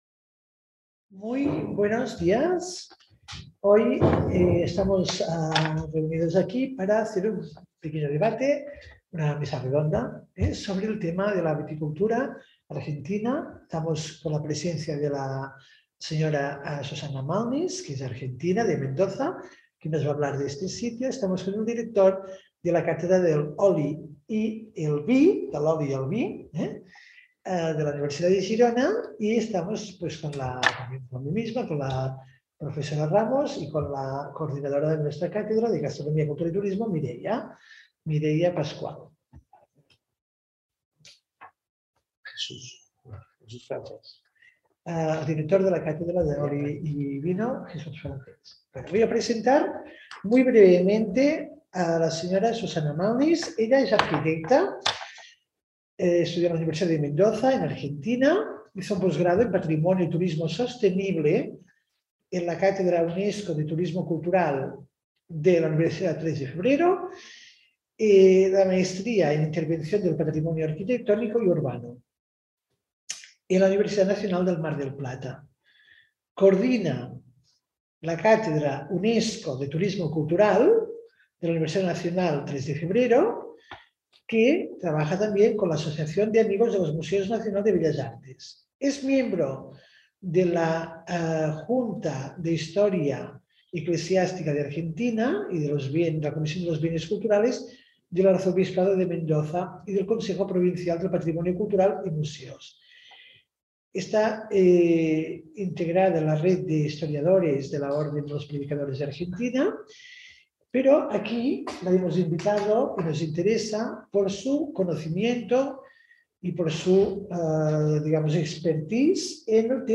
Enoturismo en Argentina y Debate sobre viticultura en Argentina y en Empordà